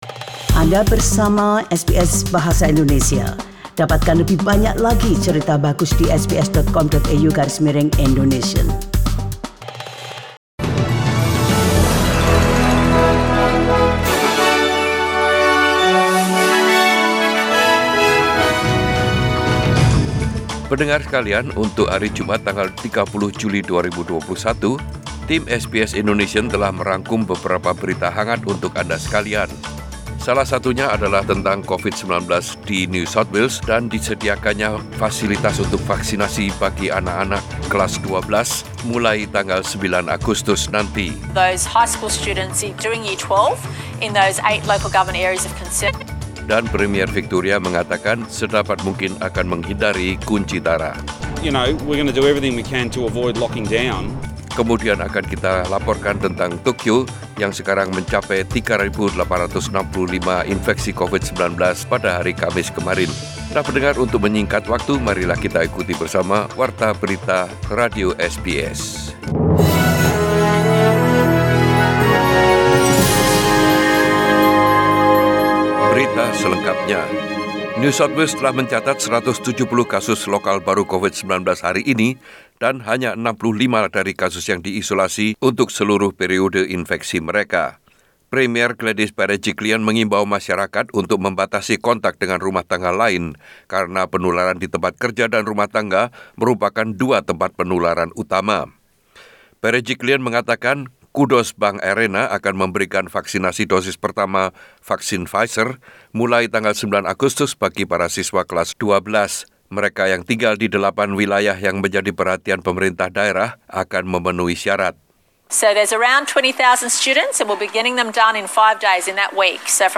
SBS Radio News in Bahasa Indonesia - 30 July 2021